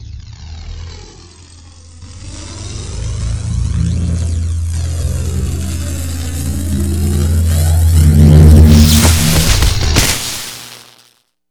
lightning_chargeup.ogg